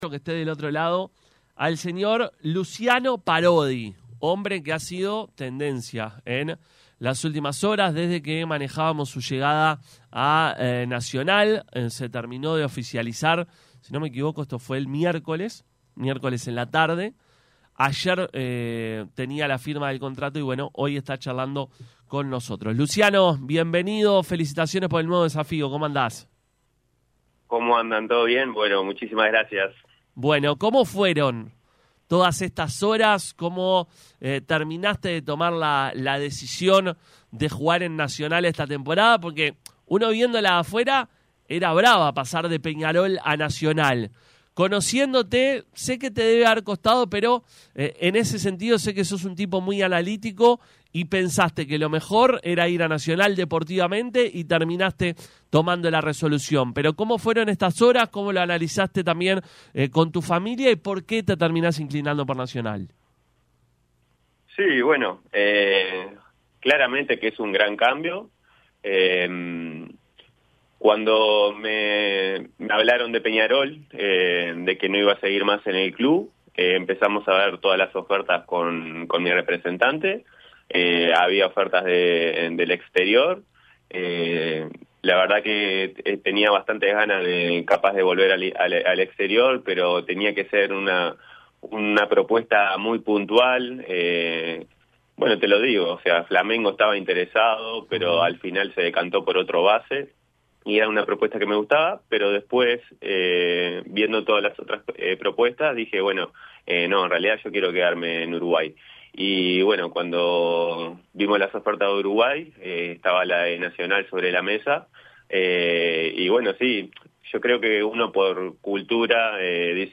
El sanducero fue tendencia en los últimos días, luego de que se confirmaba que dejaba Peñarol para jugar en Nacional la próxima LUB. Sobre esto y otros temas, hablamos con el jugador que esto nos decía: